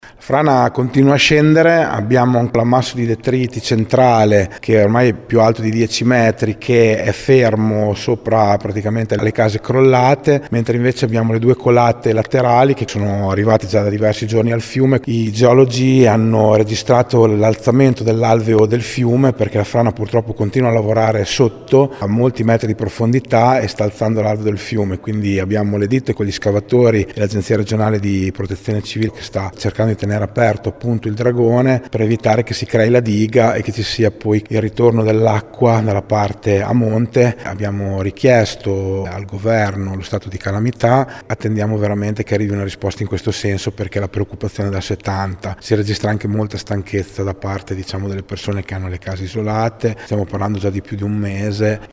Intanto la gente del posto, ad un mese dal primo movimento della frana, inizia ad essere stanca della situazione, come conferma il sindaco di Palagano Fabio Braglia: